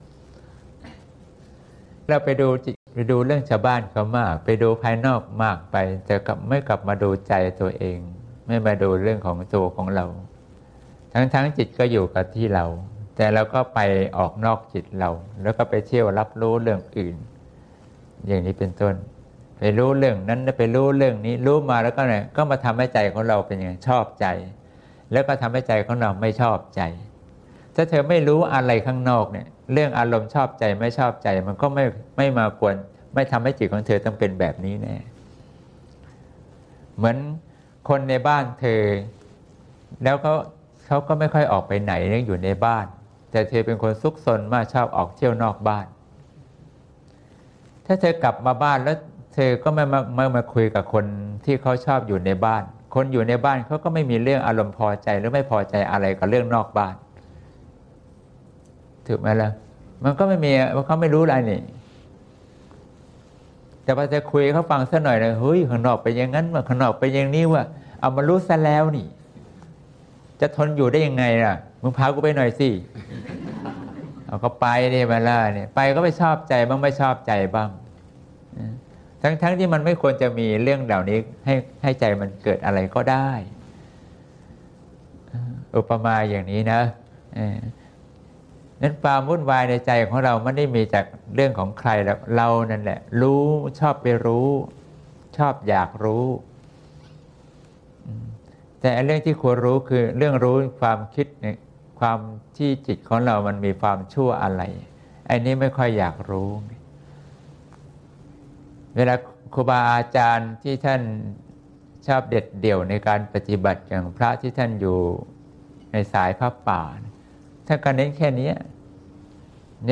เสียงธรรม (เสียงธรรม ๒๔ พ.ค. ๖๘)